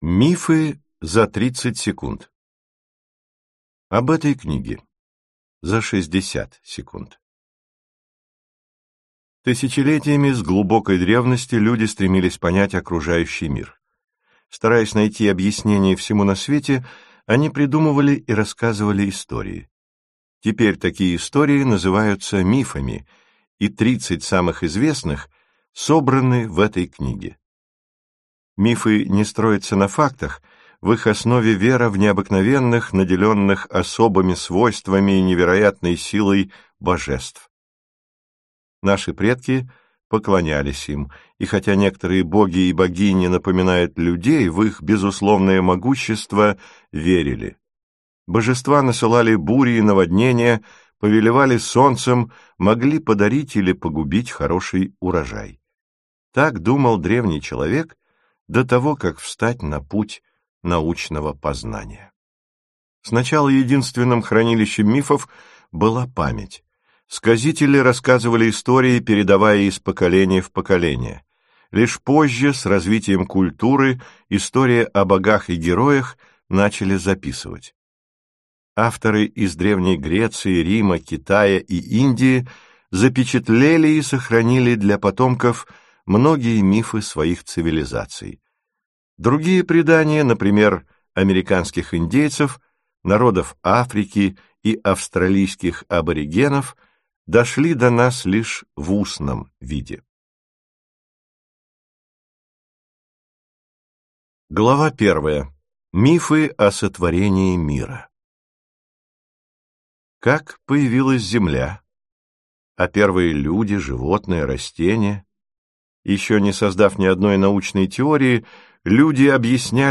Аудиокнига Мифы за 30 секунд | Библиотека аудиокниг